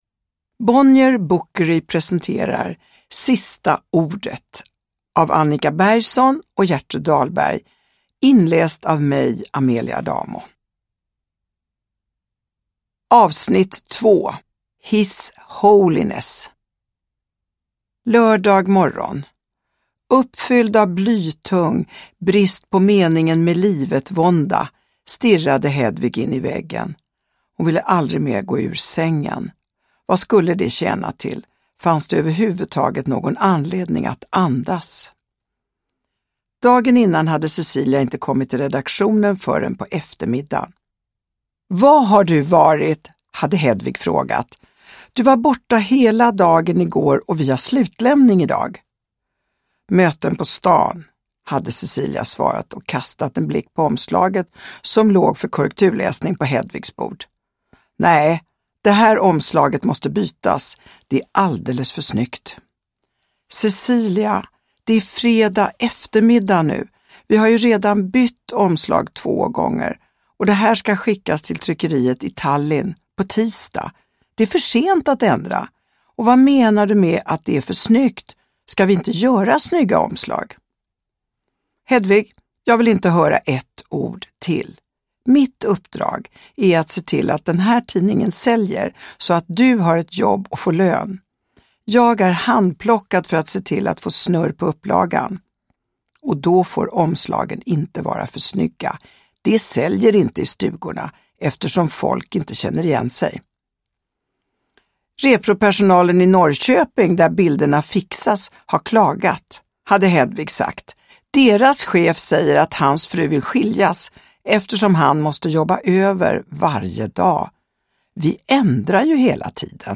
Sista ordet. S1E2, His Holiness – Ljudbok – Laddas ner
Uppläsare: Amelia Adamo